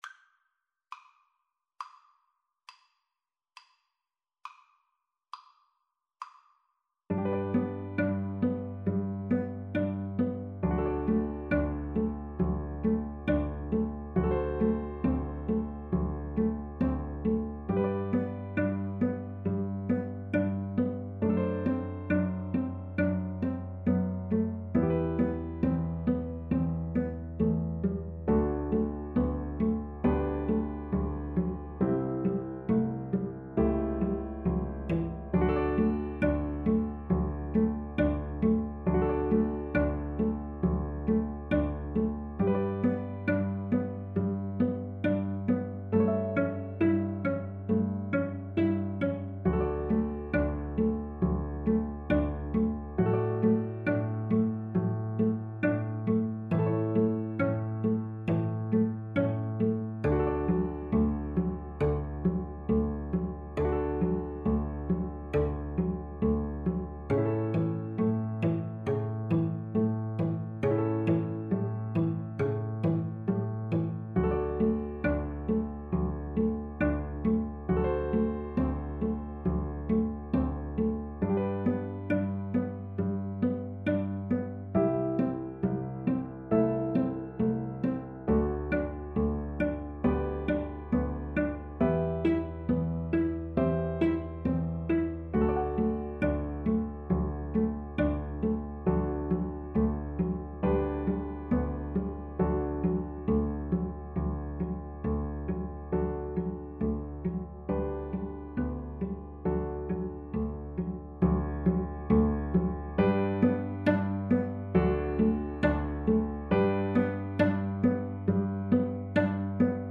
G major (Sounding Pitch) (View more G major Music for Flute-Cello Duet )
= 34 Grave
Classical (View more Classical Flute-Cello Duet Music)